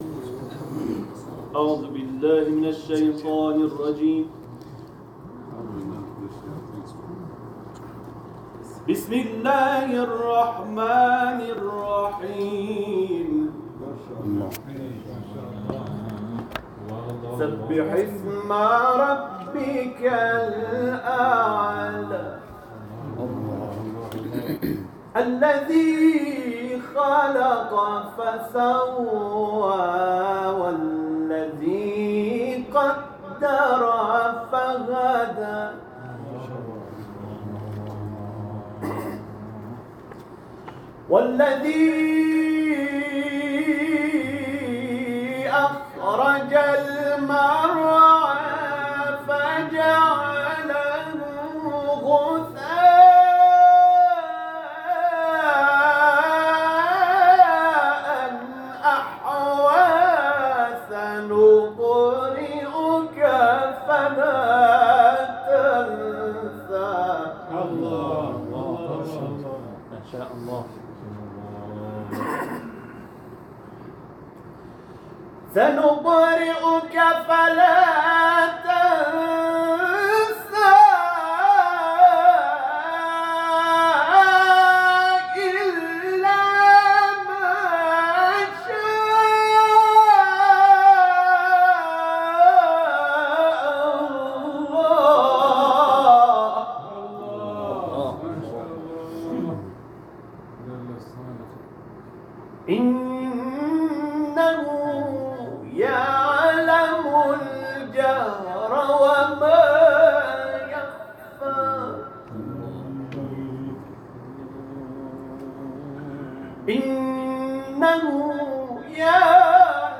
Etiketler: aktivist ، Kuran tilaveti ، İranlı kâri